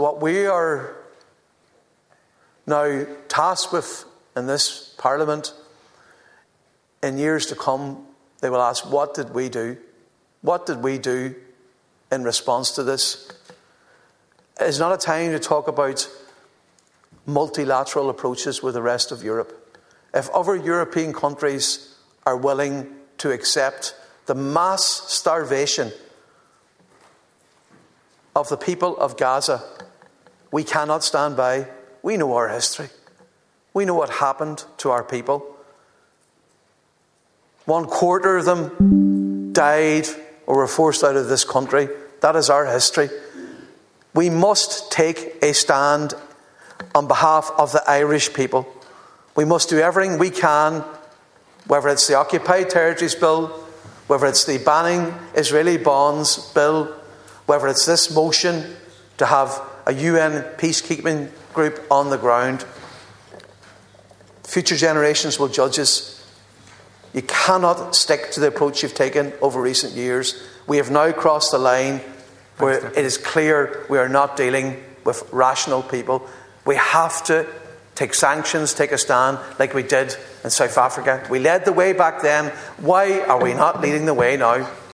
Speaking on that motion, Donegal Deputy Padraig MacLochlainn said Ireland needs to be on the right side of history and led the way: